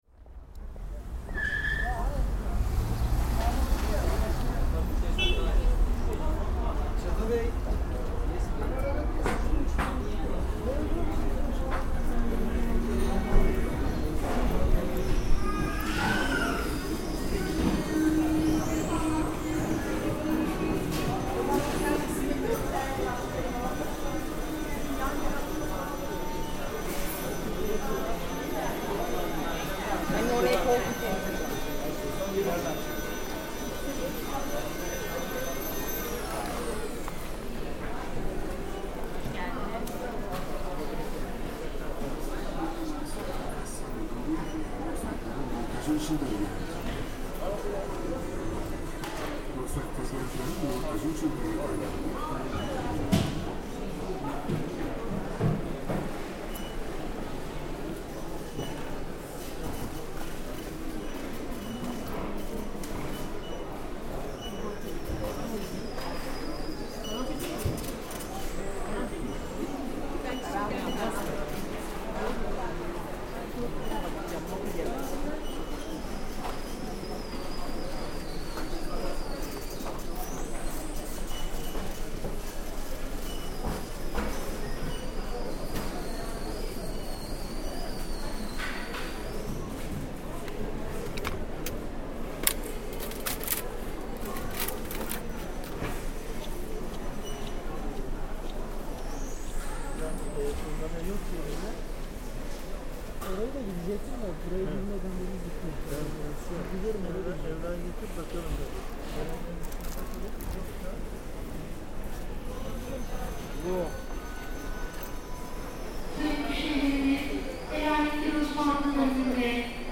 The recording starts outside the main door, from the parking lot.
Forklifts, cardboard, cutting, hammering, announcements on loudspeakers and commercials on TV sets, all kinds of materials being handled, people evaluating everything around them.
Listen to the sounds of shopping, building and construction at Bauhaus: